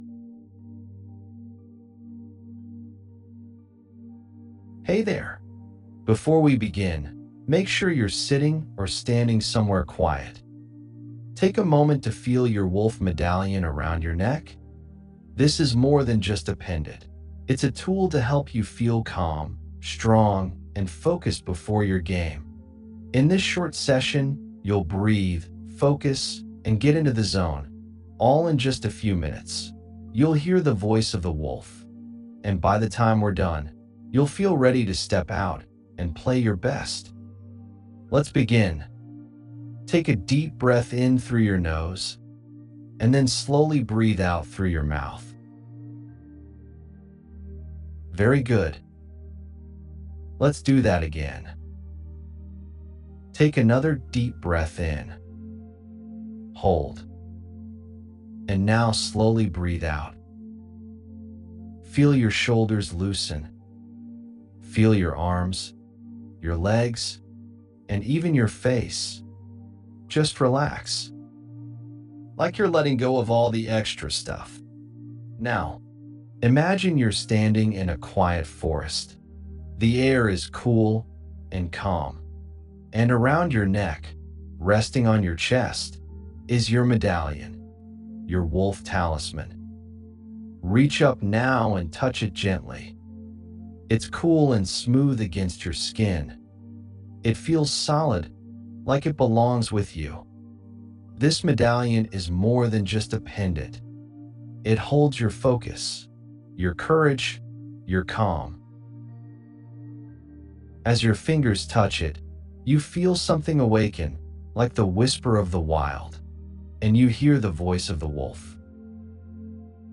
mindfulness_ARF_demo.mp3 $24.95 Recording Extract
5-Minute Guided Audio: A calming session that blends mindfulness and performance psychology, empowering your child to feel confident and in control before every game.